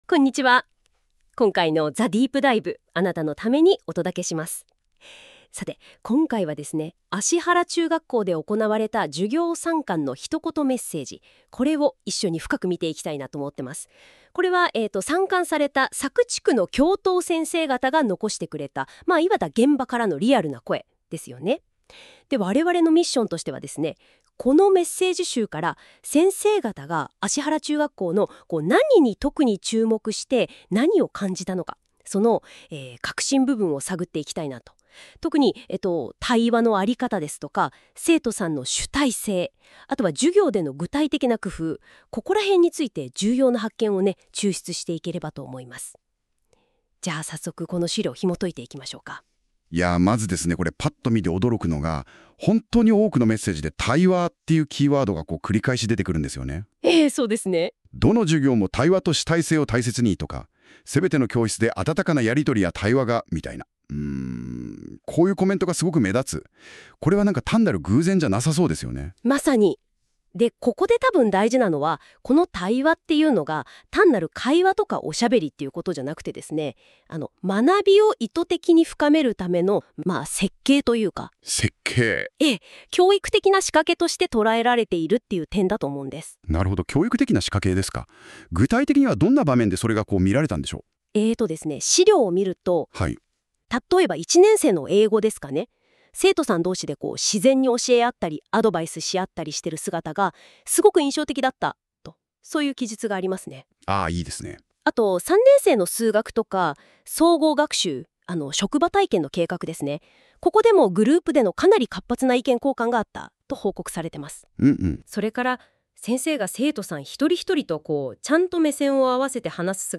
８月２６日（火）に実施された 佐久地区 中学・高校教頭会で寄せられた 「ひとことメッセージ」を生成AIが分析し、 ポッドキャスト（ラジオ番組風コンテンツ）を 制作してくれました。